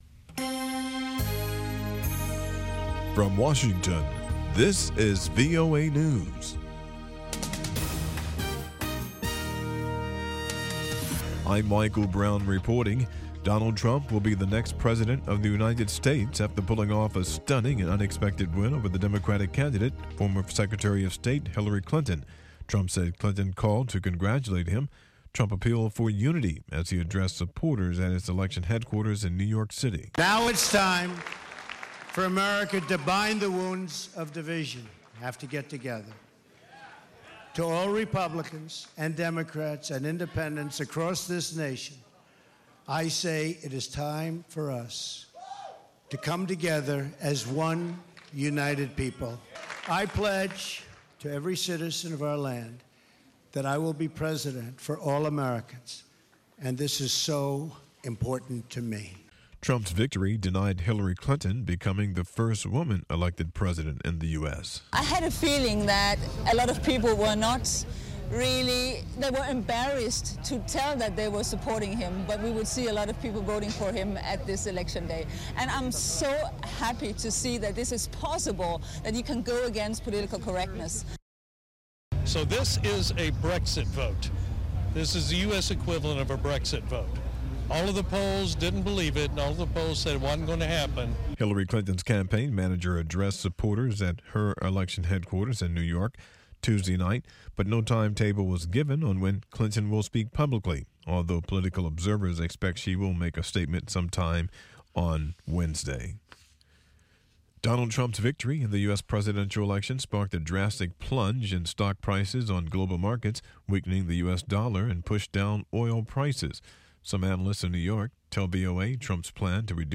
1100 UTC Newscast for November 9, 2016